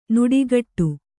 ♪ nuḍigaṭṭu